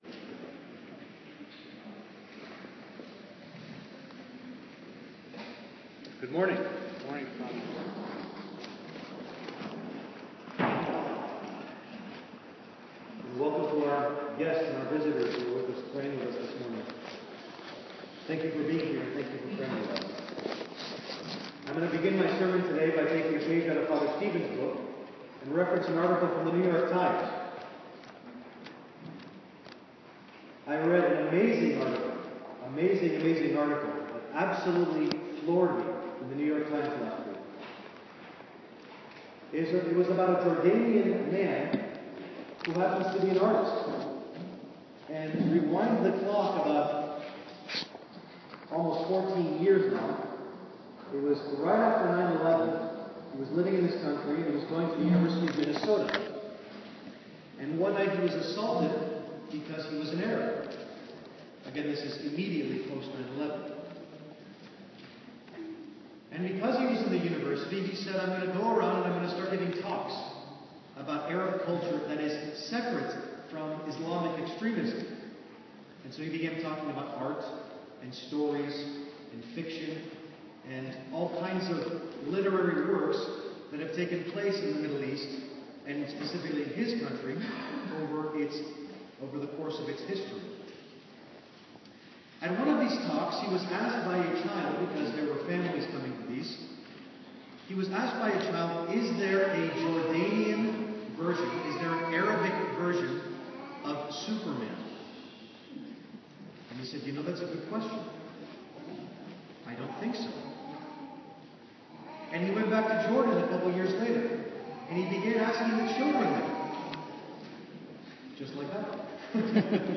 Sermon Audio- “Be A Hero”
Please excuse the lower quality as someone recorded it on their iPhone and emailed it to me the next week.